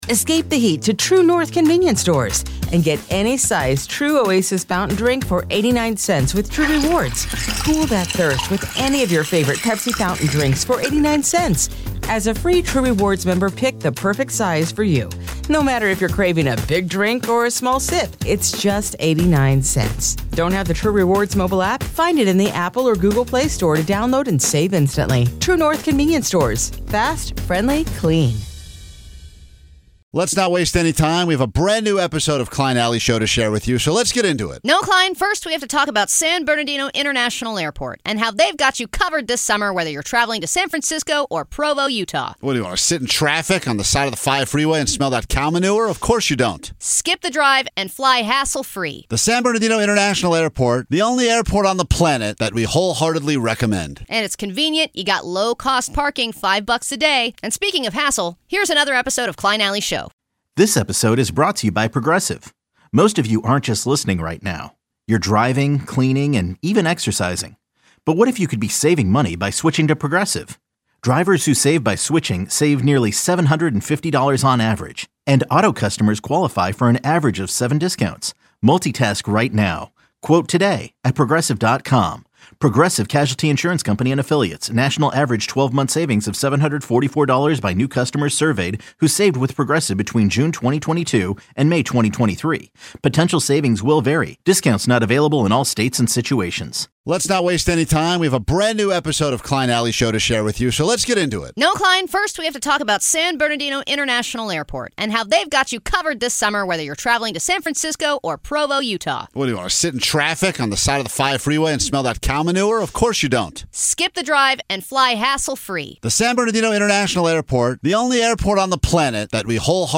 the show is known for its raw, offbeat style, offering a mix of sarcastic banter, candid interviews, and an unfiltered take on everything from culture to the chaos of everyday life.